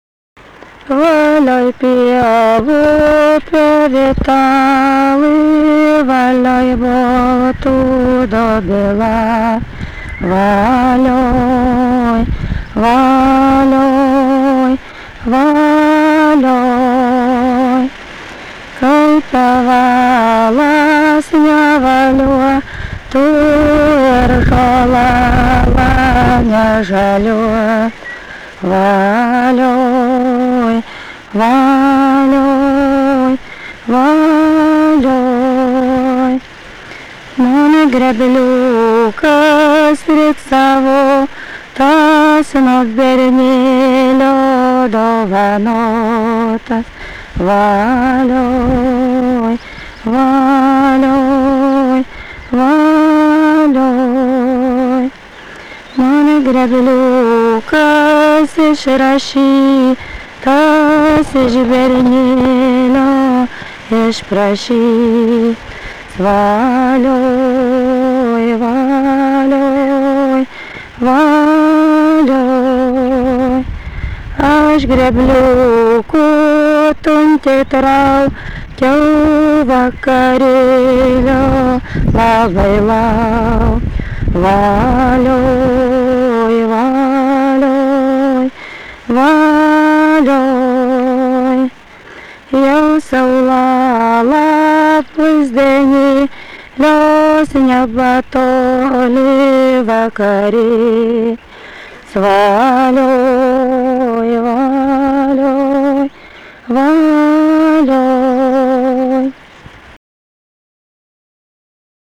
daina, kalendorinių apeigų ir darbo
vokalinis